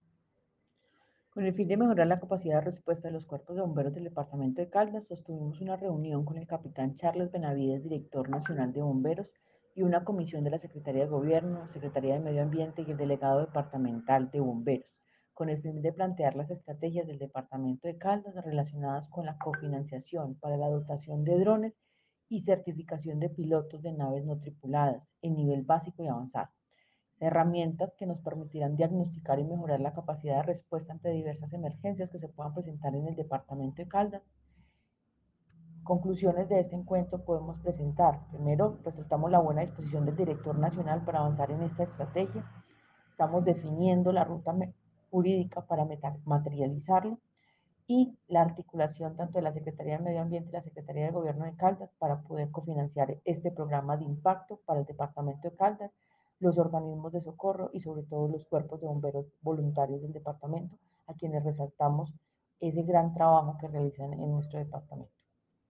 secretaria de Medio Ambiente de Caldas, Paola Andrea Loaiza